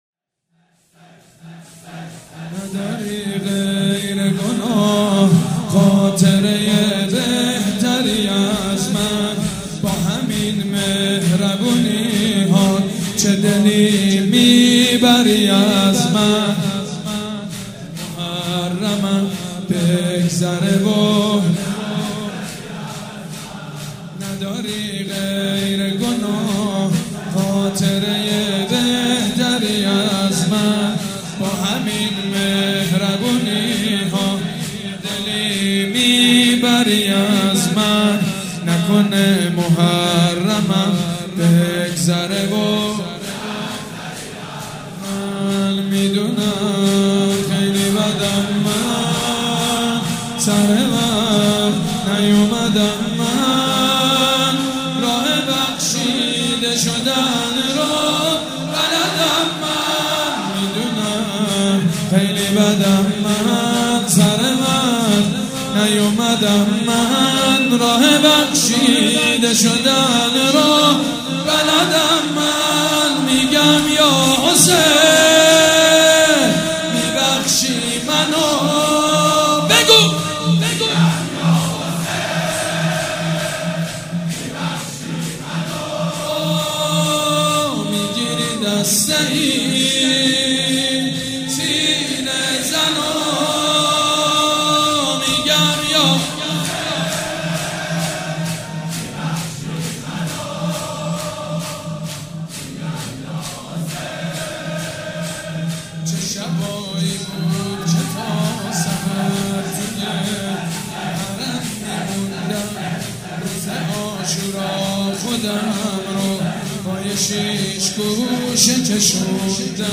شور
حاج سید مجید بنی فاطمه
مراسم عزاداری شب تاسوعا